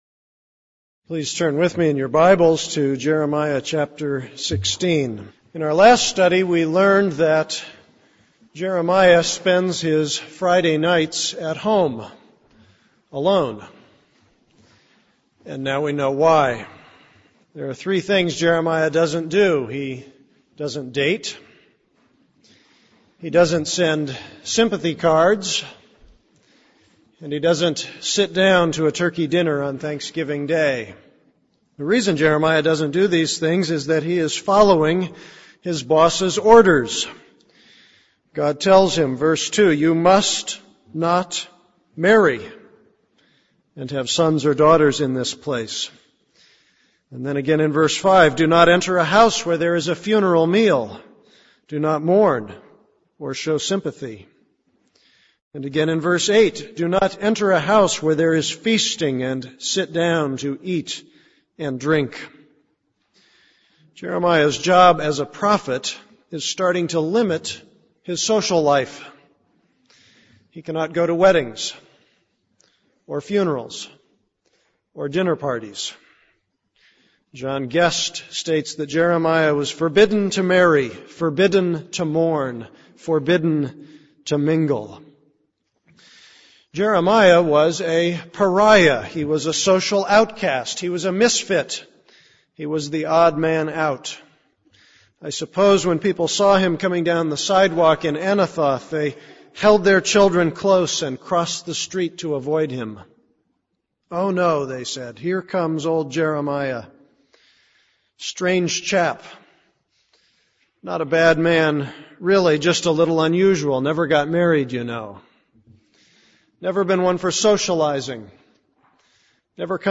This is a sermon on Jeremiah 16:1-17.